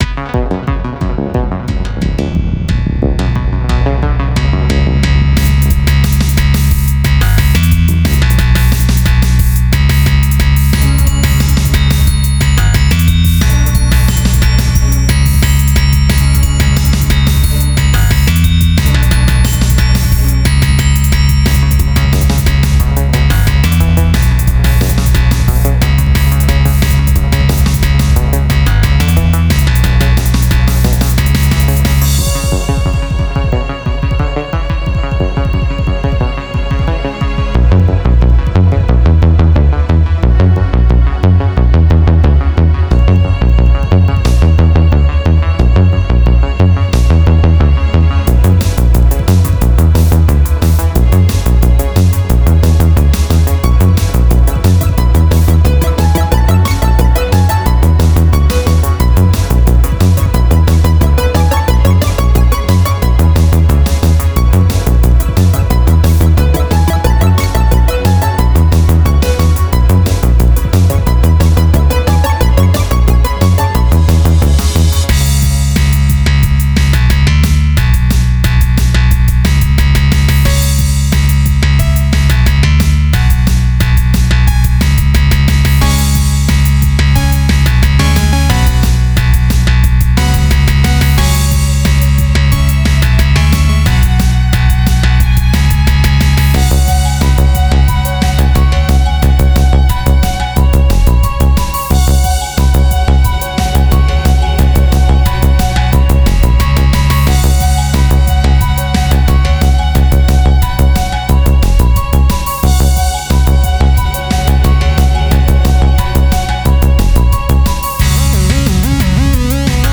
I espeacially like the drum beat in the background!